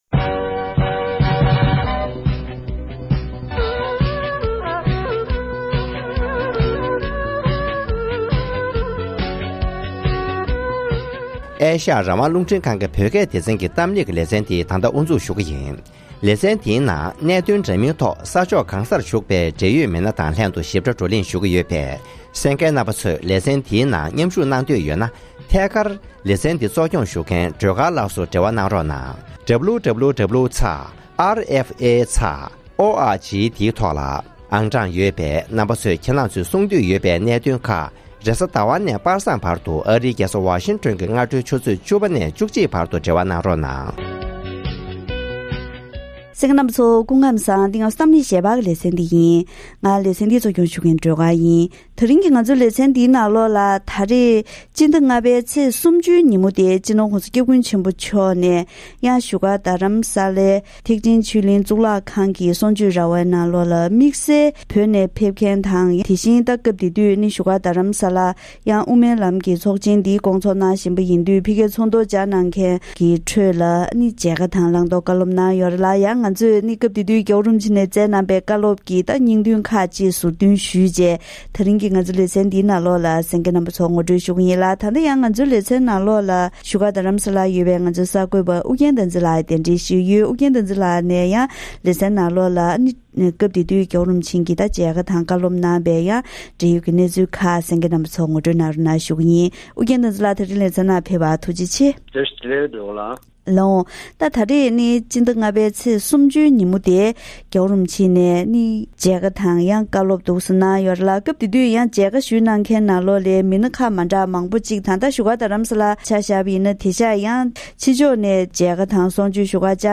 ༧གོང་ས་མཆོག་ནས་དབུ་མའི་ལམ་གྱི་ཚོགས་འདུར་ཕེབས་མཁན་གྱི་གཙོས་པའི་བོད་མི་ཁག་ཅིག་ལ་མཇལ་ཁ་བཀའ་སློབ་གནང་བ།